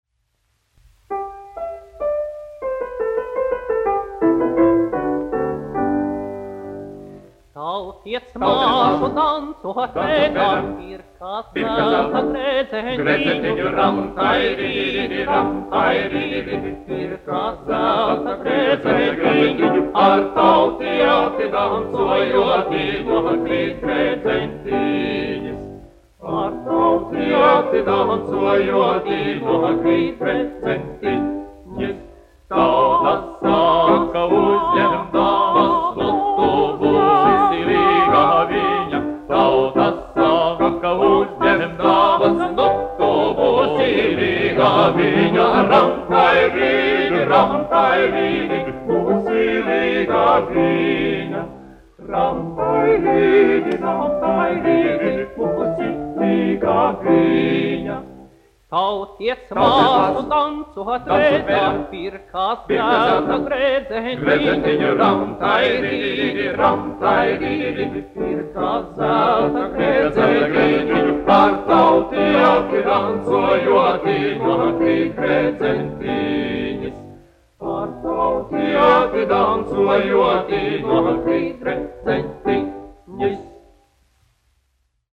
Mugurdancis : latviešu tautas deja
Latvijas Filharmonijas vīru vokālais kvartets, izpildītājs
1 skpl. : analogs, 78 apgr/min, mono ; 25 cm
Vokālie kvarteti
Latvijas vēsturiskie šellaka skaņuplašu ieraksti (Kolekcija)